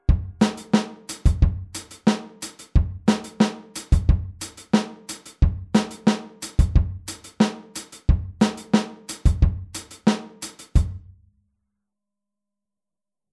Nach dem 4tel-Offbeat setzen wir ein Echo hinzu
Hier spielen wir den Offbeat mit der rechten Hand wieder auf dem HiHat.
Groove03-16off.mp3